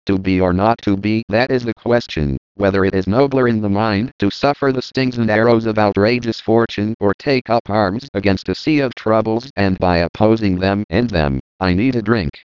using Bell labs voice synthesis